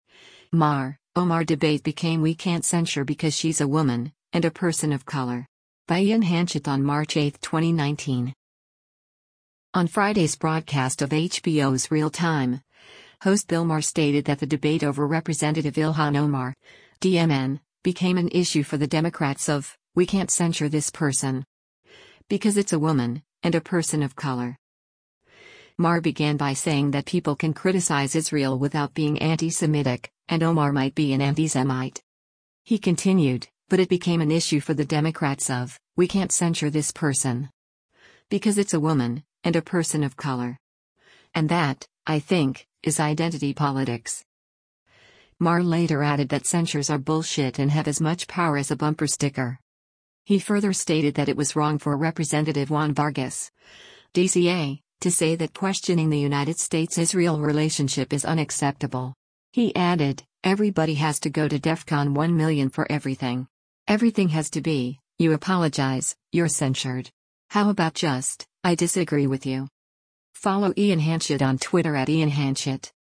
On Friday’s broadcast of HBO’s “Real Time,” host Bill Maher stated that the debate over Representative Ilhan Omar (D-MN) “became an issue for the Democrats of, we can’t censure this person. Because it’s a woman, and a person of color.”